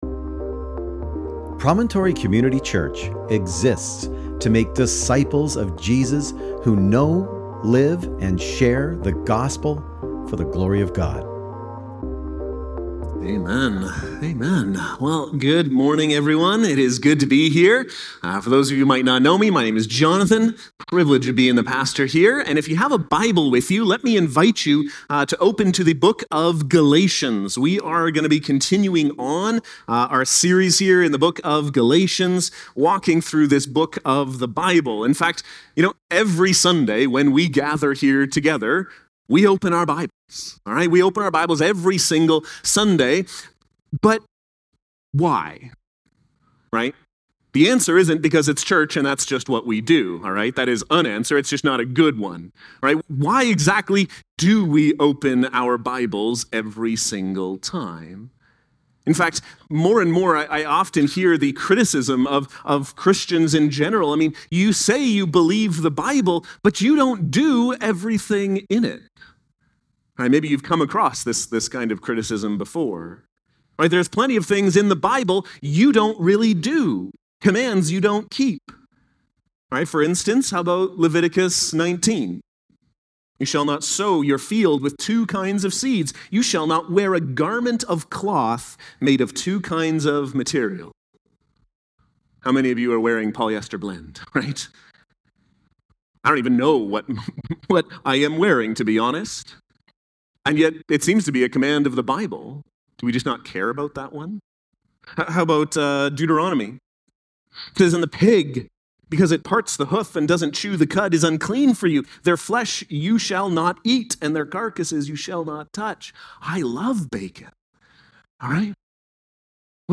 Sermon Transcript: (transcribed with AI) Well, good morning everyone, it is good to be here.